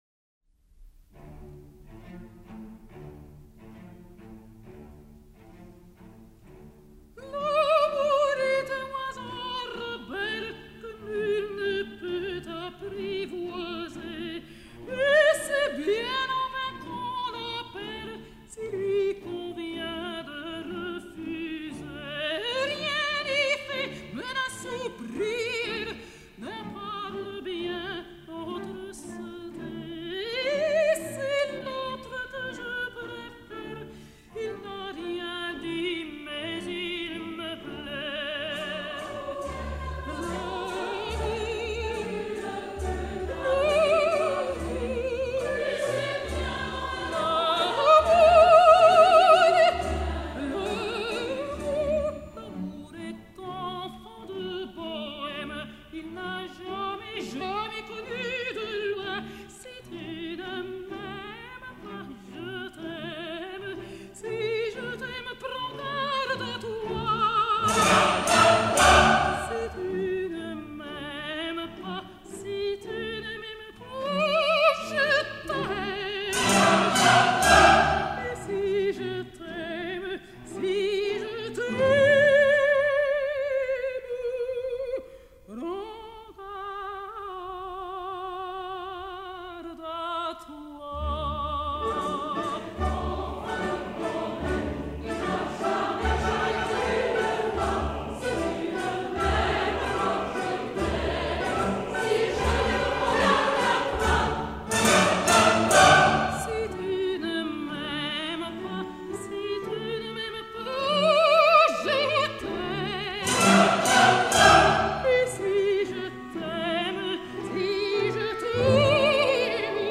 以下挑選的曲子是最為大家所熟悉的「哈巴內拉舞曲」,由卡拉絲和巴爾莎這兩位女高音演唱的版本比較。 哈巴內拉舞曲，為劇中第一幕卡門出場時所唱的詠歎調。